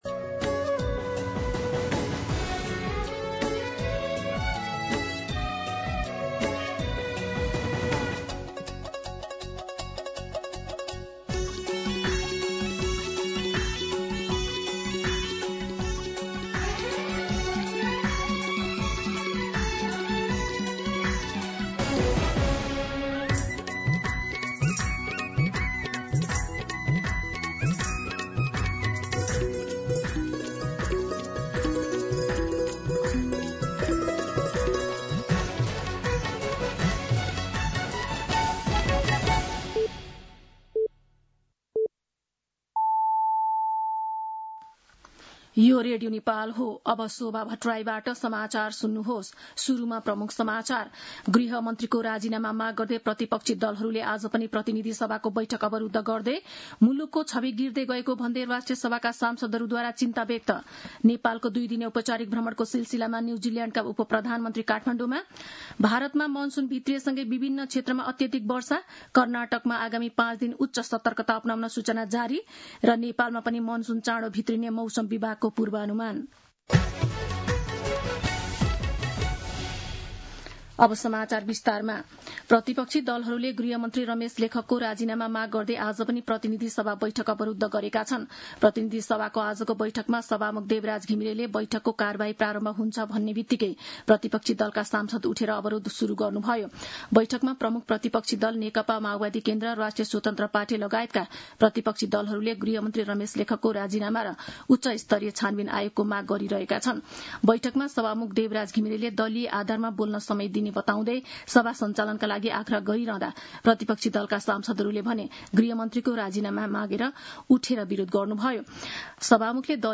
दिउँसो ३ बजेको नेपाली समाचार : १४ जेठ , २०८२
3pm-News-02-14.mp3